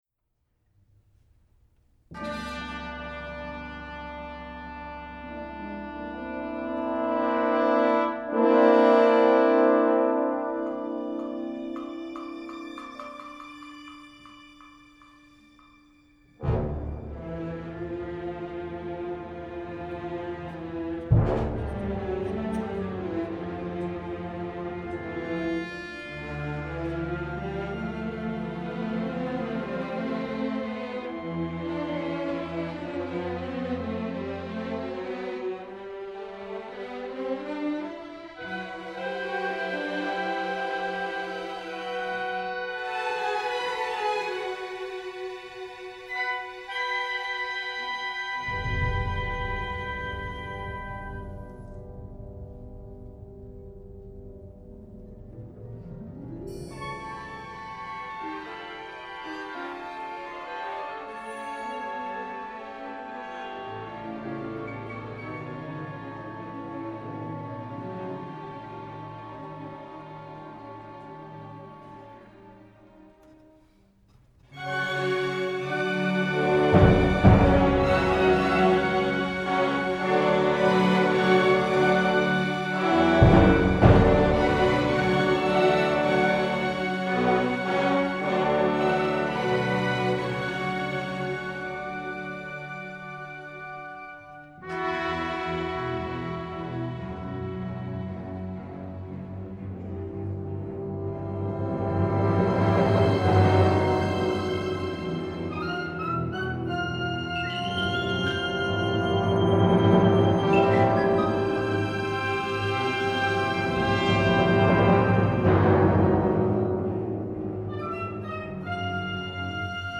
• Thriller (00:00), (1:50), (2:17), (3:45), (4:44), (6:34);
• Horror (00:00), (2:17), (3:45), (4:44);